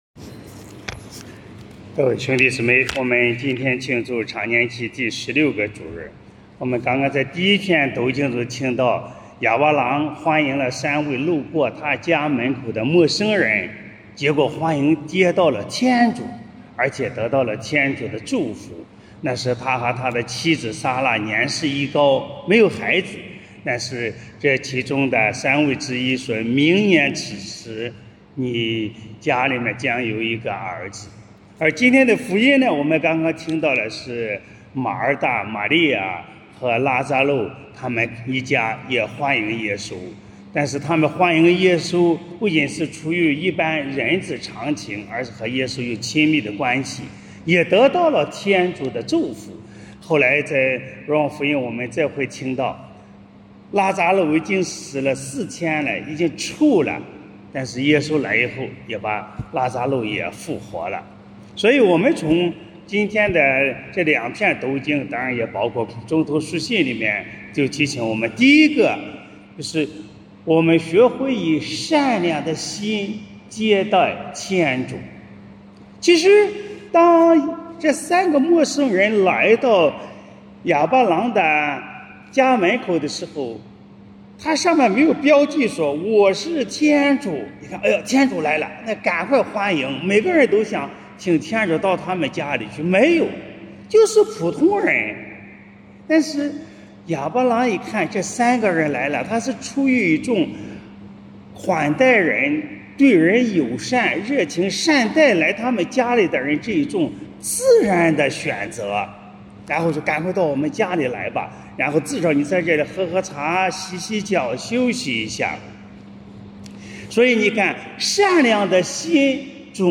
【主日证道】| 祈祷就是工作（丙-常年期第16主日）